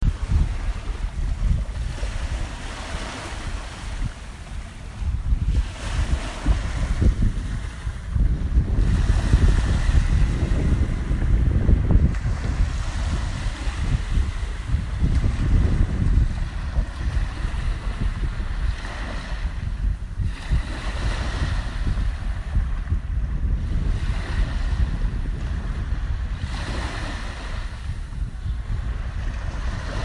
大气 " 海洋 温和的波浪
描述：海滩上温柔的海浪的一般气氛。Roland R26内部全向+XY话筒混合
Tag: 现场录音 海滨 大气 Gentlewaves